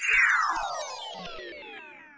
На этой странице собраны оригинальные звуки Windows 95: старт системы, уведомления, ошибки и другие знакомые мелодии.
Музыка Windows 95 Utopia корзина звук ретро операционная система